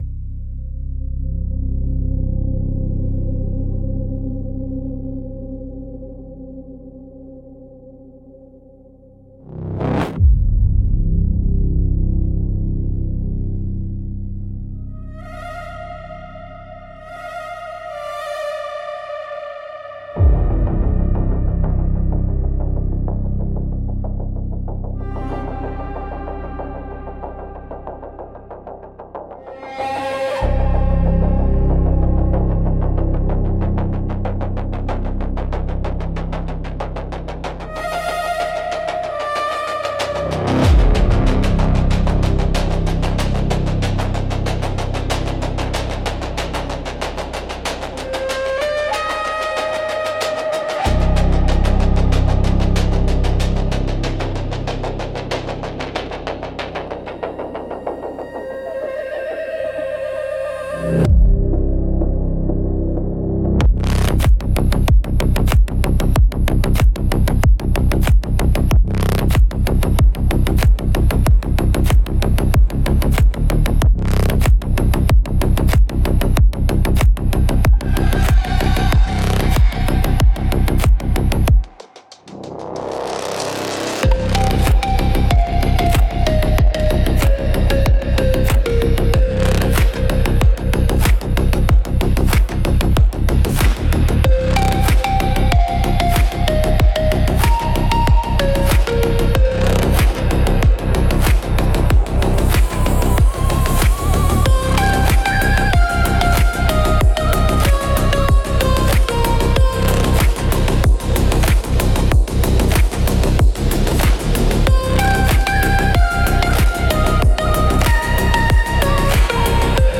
Instrumentals - Fossilized Frequency